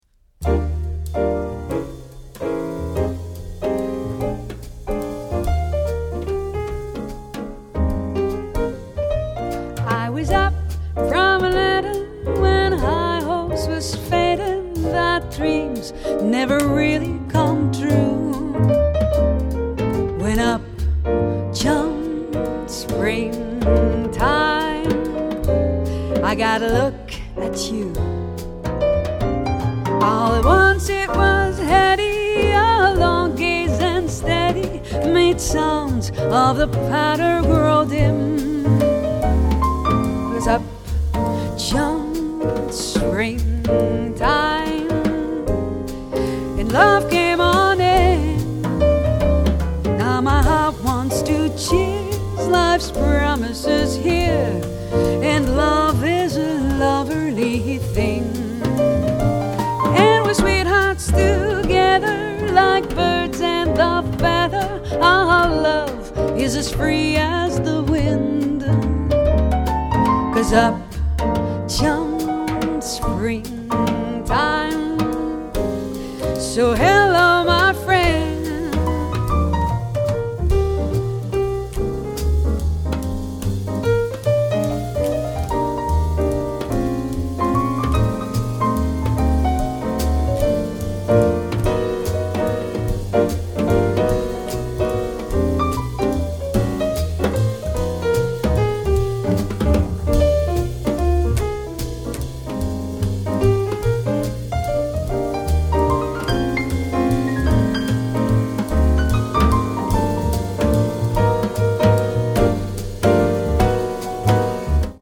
★ 經典爵士曲風，優雅靈氣展現四季時序變遷氛圍！
★ 天王級錄音大師親自操刀，類比直刻展現完美音效！